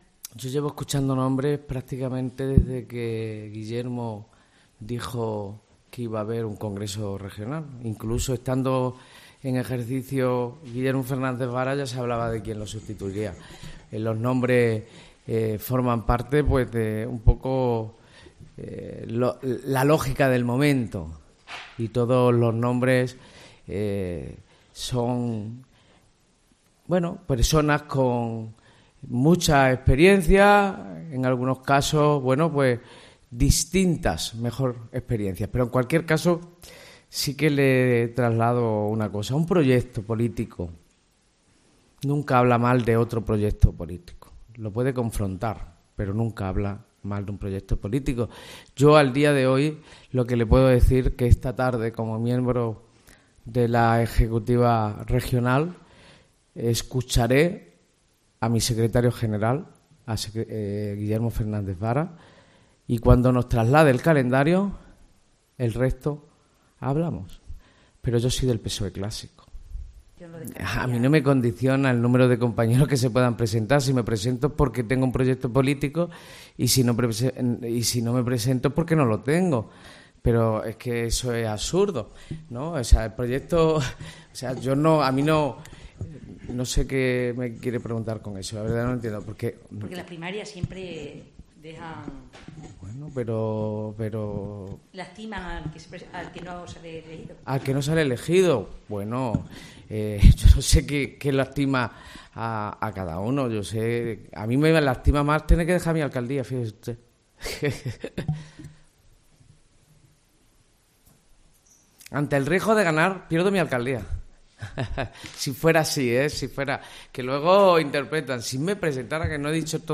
El socialista Miguel Ángel Gallardo ha vuelto a hablar este viernes, a preguntas de la prensa, sobre su posible candidatura a liderar el PSOE extremeño.